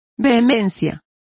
Complete with pronunciation of the translation of intensities.